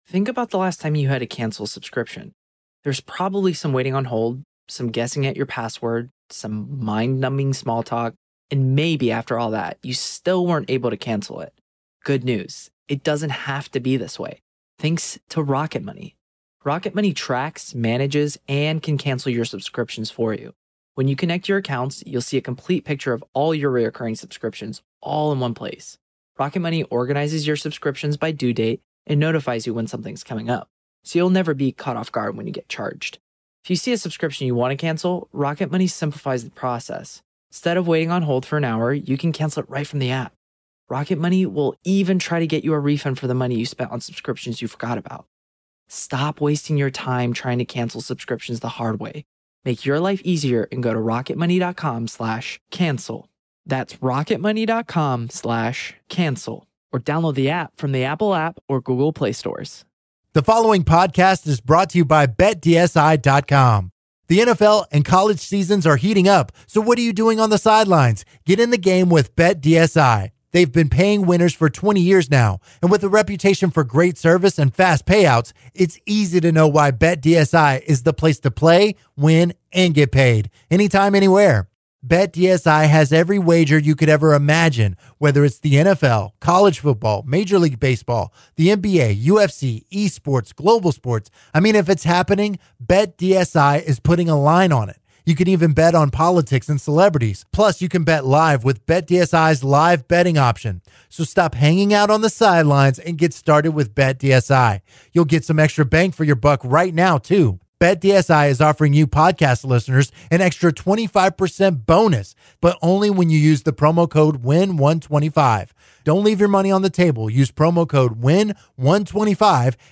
Broadcasting live from Texas Mattress Makers